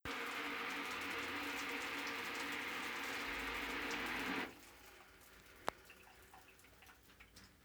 Final de una ducha
Grabación sonora del sonido del final de una fucha, en la que el agua que cae de manera intensa para de hacerlo para caer de manera más suave hasta que finalmente se corta.
Sonidos: Agua
Sonidos: Acciones humanas